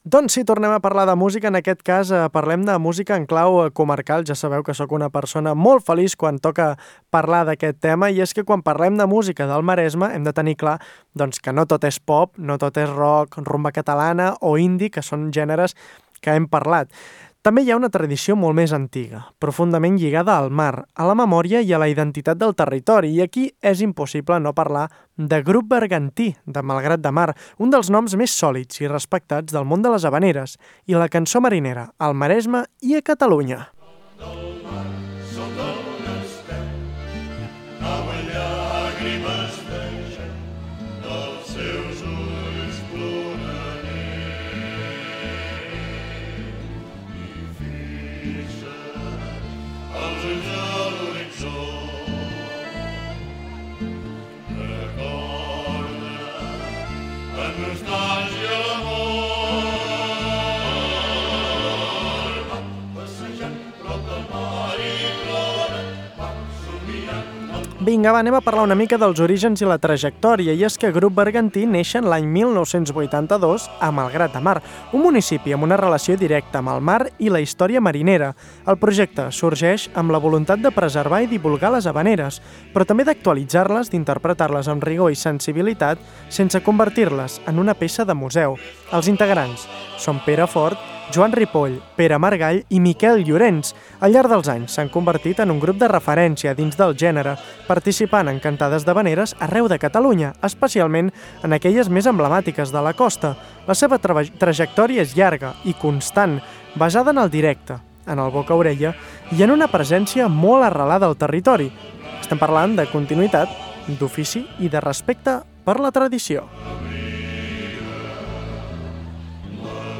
Al programa comentem la seva trajectòria, el repertori i la manera com interpreten aquestes cançons marineres que formen part de la memòria col·lectiva. Una conversa per conèixer el grup malgratenc, el seu vincle amb el territori i el paper de les havaneres avui dia.